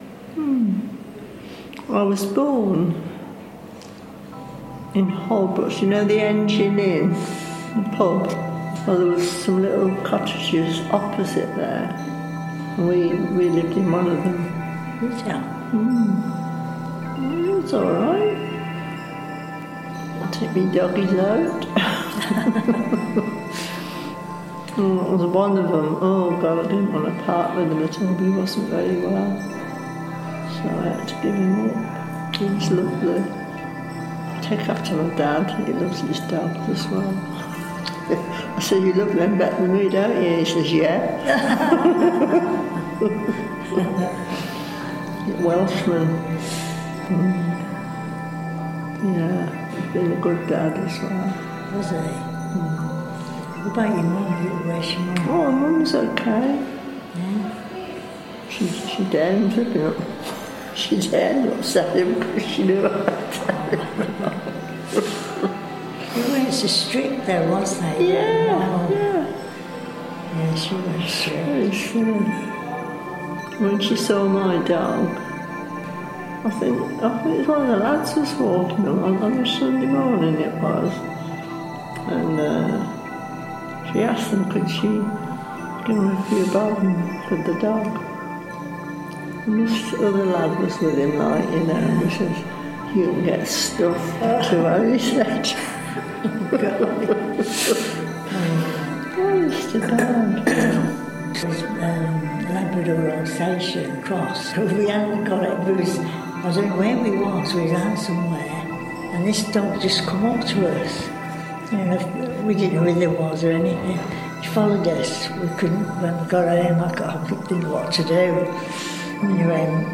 Theatre of Wandering Creative Soundscapes #7